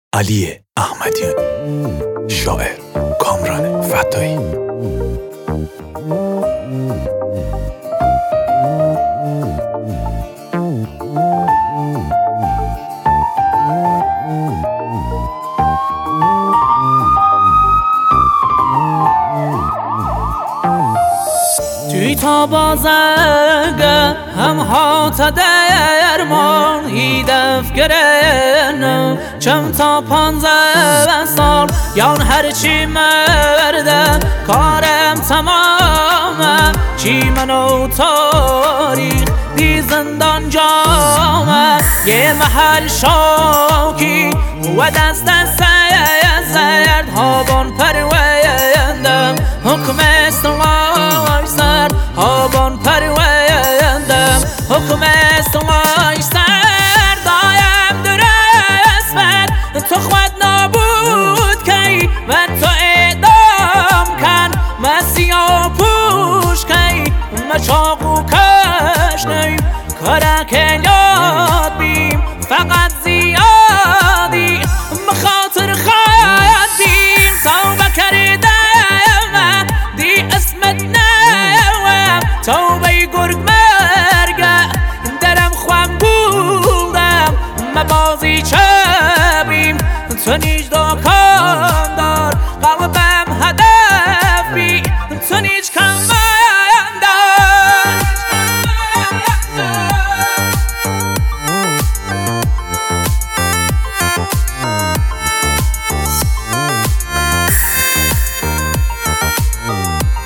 کردی